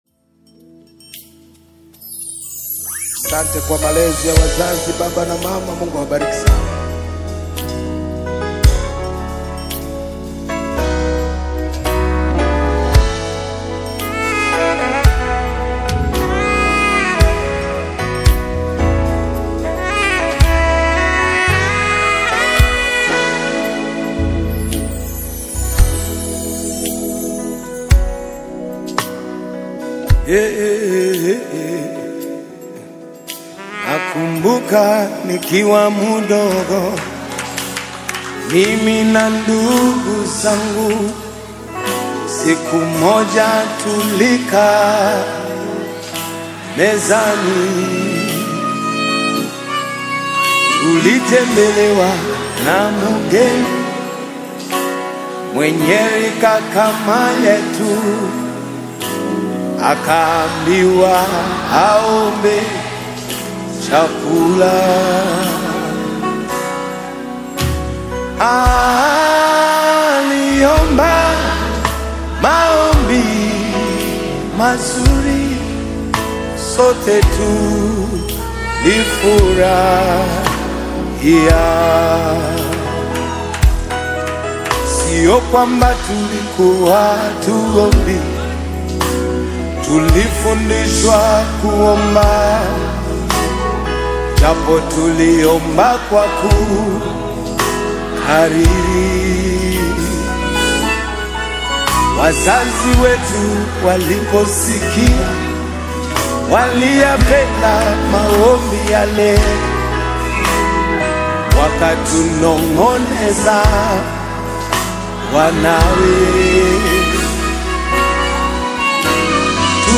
Gospel
a worship track
sincere vocals over clean, polished production